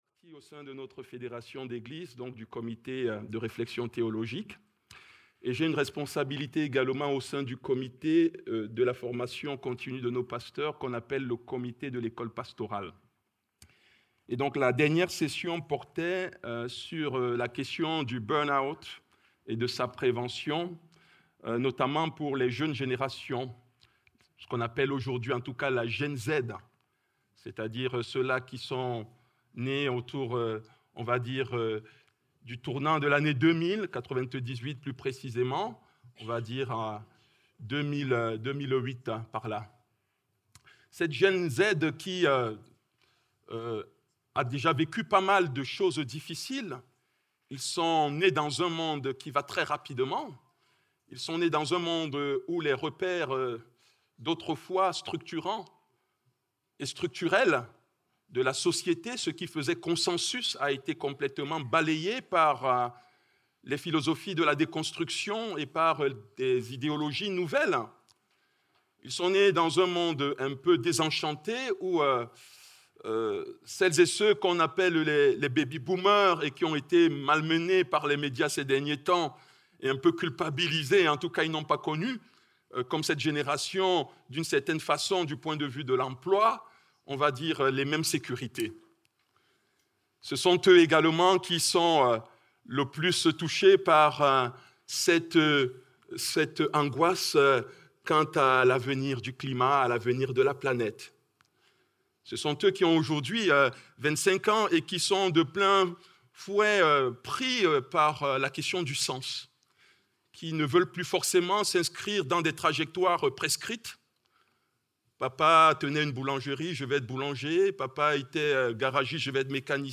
Culte du dimanche 22 mars 2026, prédication donnée par le pasteur